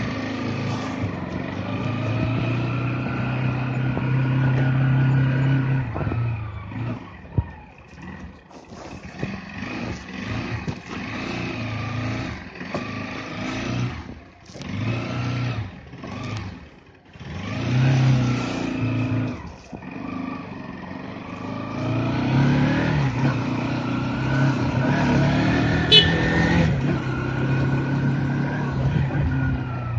描述：Sonido de moto en movimiento en zona urbana de Cali。